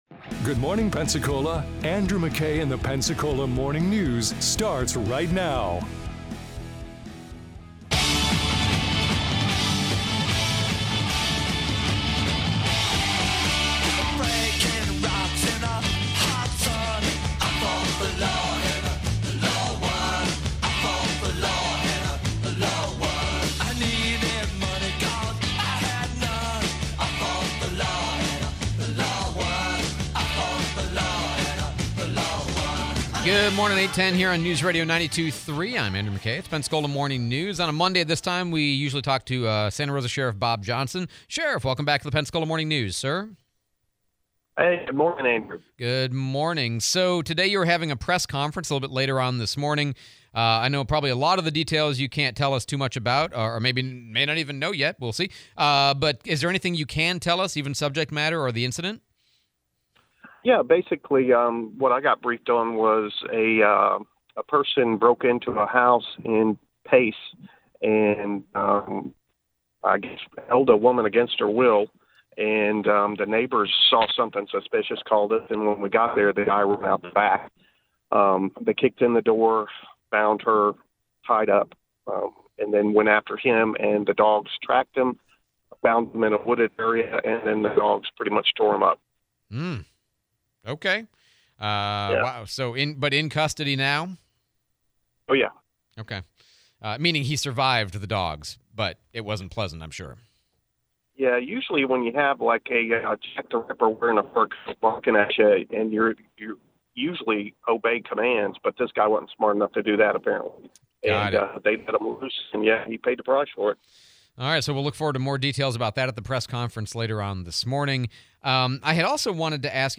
Interview with SRC Sheriff Bob Johnson / Frivolous Topic: What is your preferred water vessel?